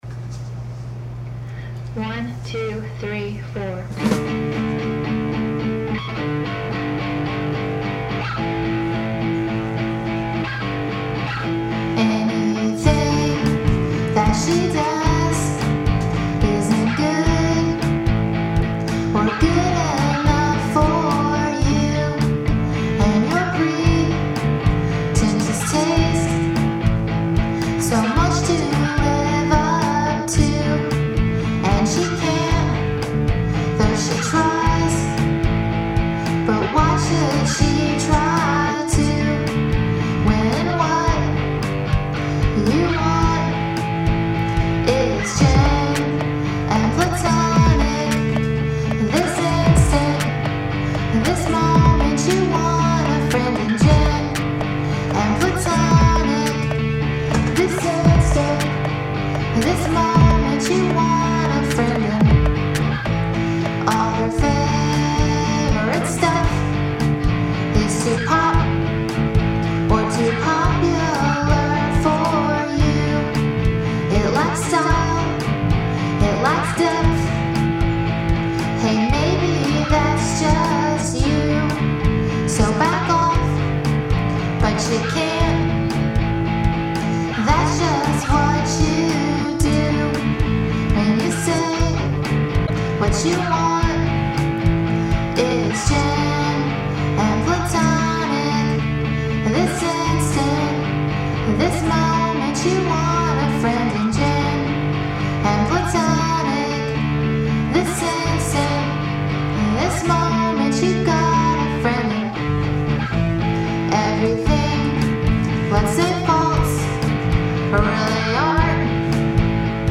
I mixed it on my Mac.  Sorry I have bad rhythm.